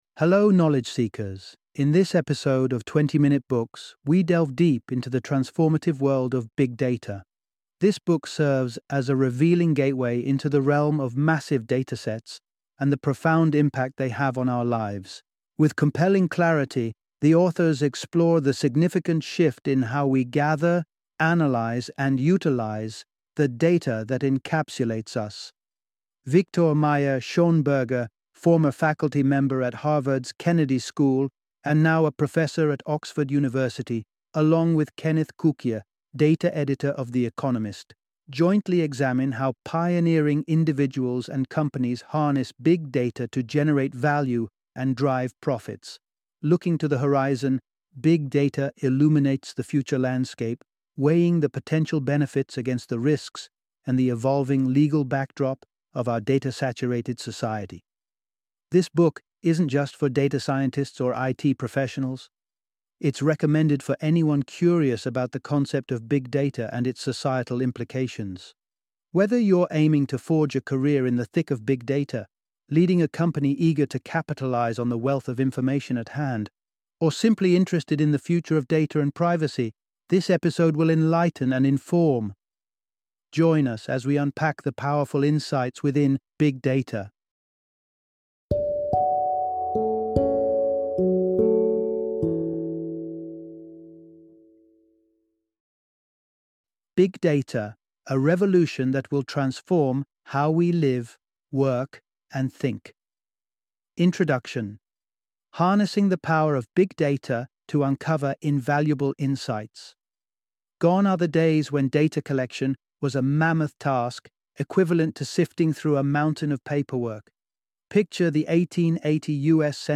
Big Data - Audiobook Summary